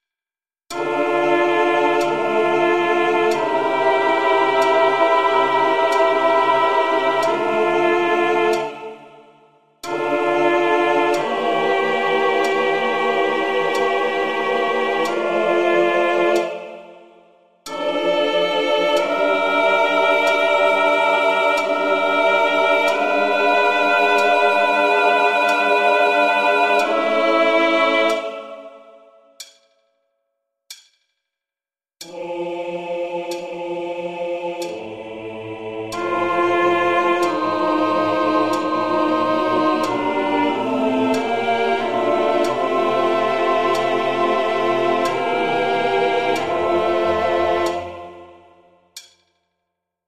音取り音源
コーラス＋メトロノーム